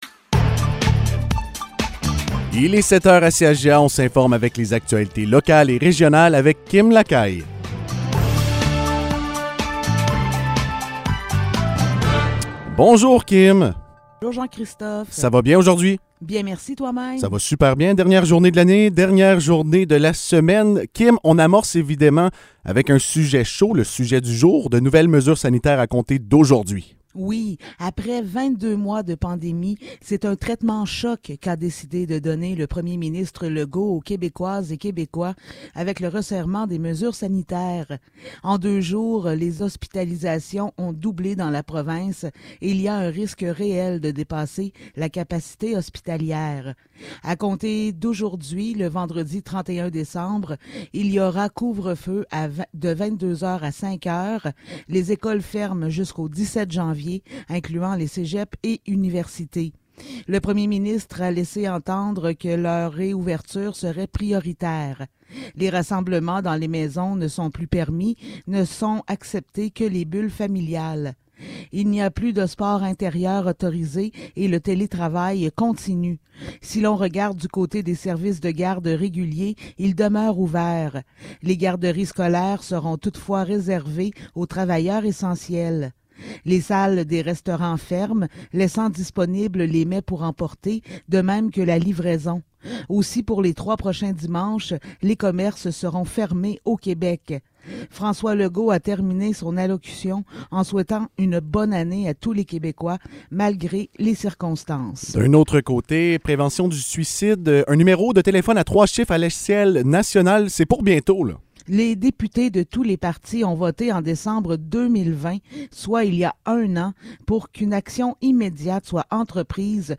Nouvelles locales - 31 décembre 2021 - 7 h